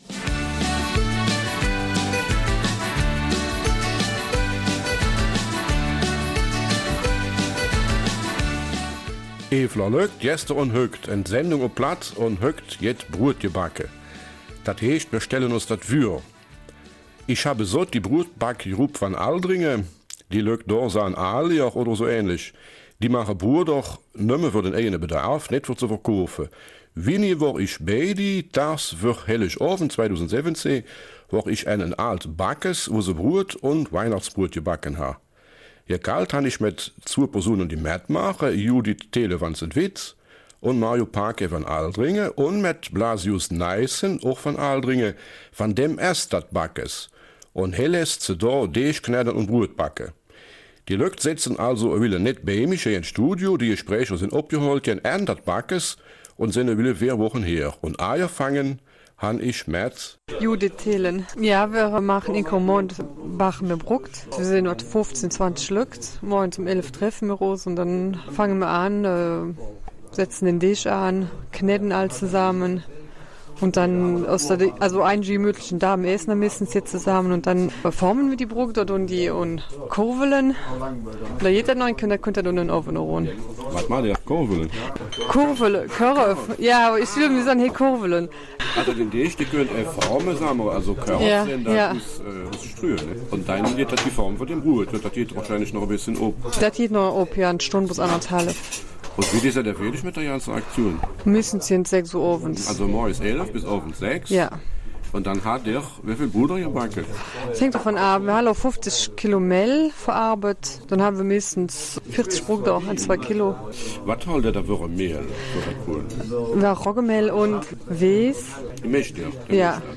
Eifeler Mundart: Die Brotbackgruppe Aldringen